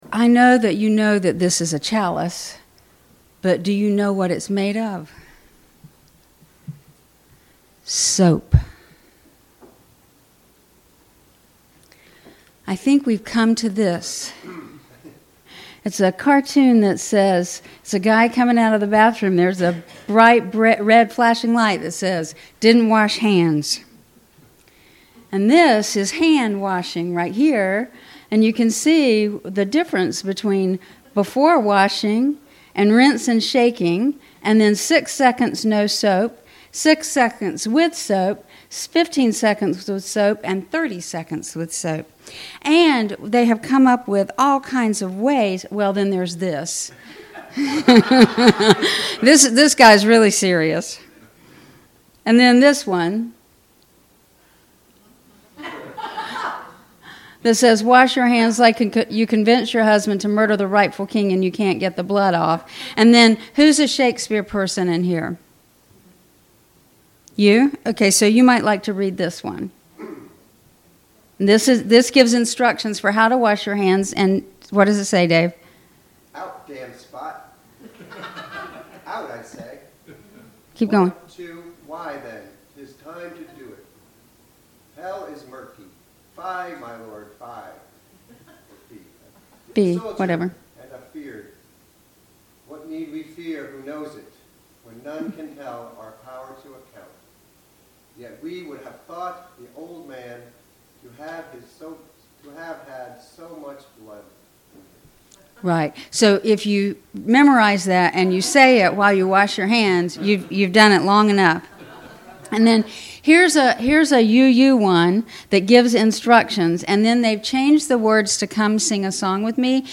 This sermon features a communal address focused on navigating the COVID-19 pandemic through a blend of humor, historical perspective, and social responsibility. The speaker uses pop culture references and songs to teach effective hand-washing techniques before shifting to a somber reflection on the 1918 influenza pandemic to warn against the dangers of isolation and fear. A central theme is the ethical obligation to protect vulnerable populations, including the disabled, the elderly, and undocumented workers who are often marginalized in public health messaging.